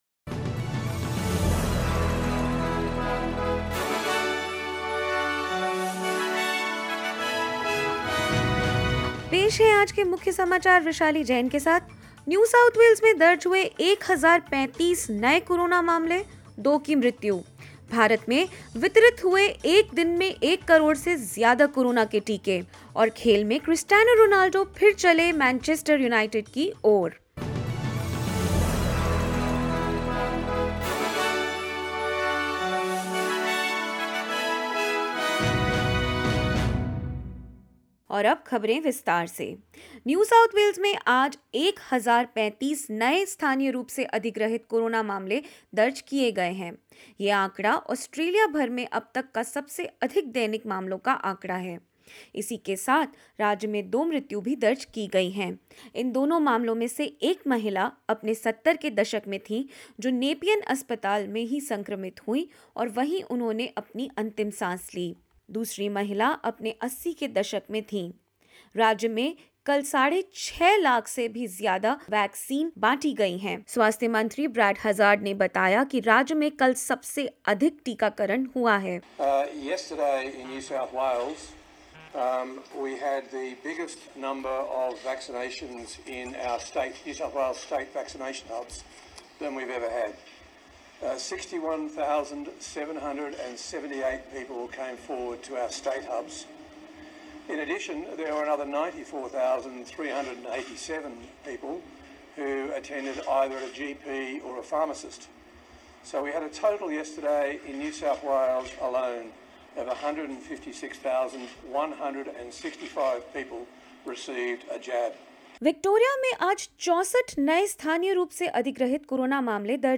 In this latest SBS Hindi news bulletin of Australia and India: NSW reports a record high of 1,035 infections and 61,000 vaccinations in a single day; Victoria registers 64, and the ACT 26 new locally acquired cases in the last 24 hours; India distributes over 10 million doses of coronavirus vaccines in one day and more.